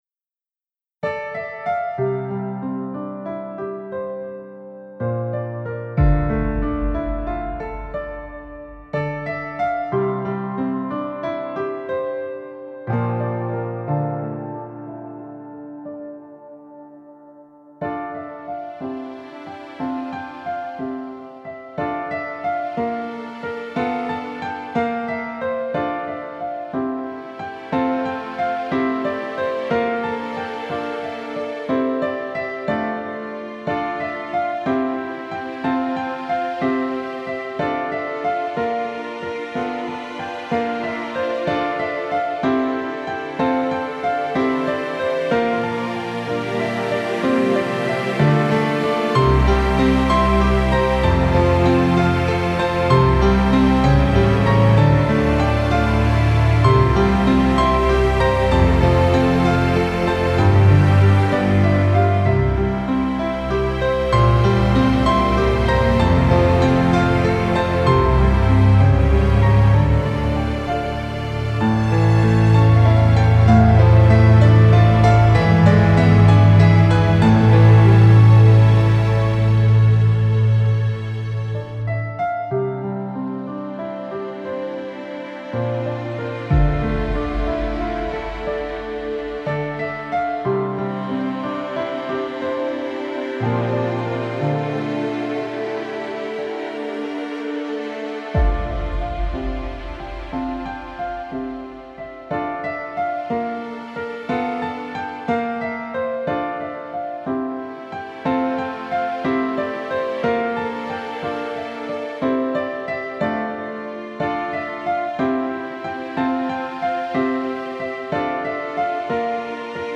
" music remake.